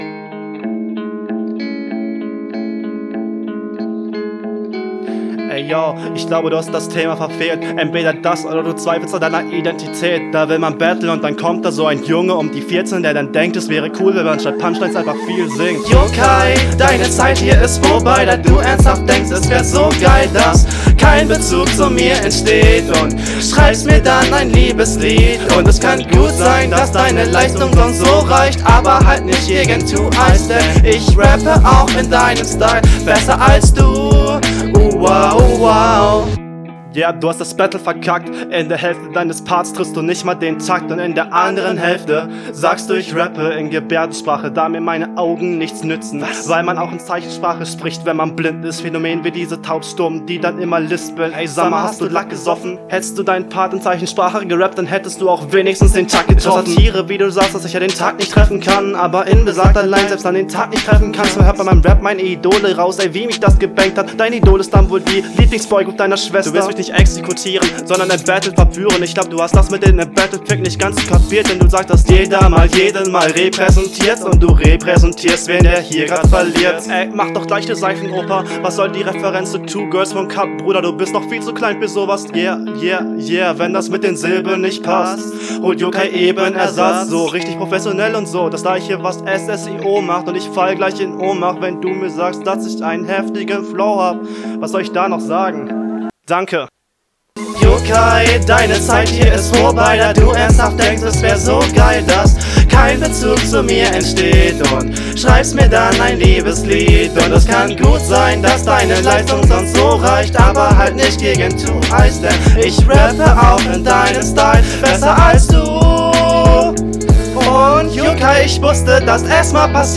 Soundqualität: Direkt schonmal ein guter Einstieg, aber man hört dass es noch etwas bei dir …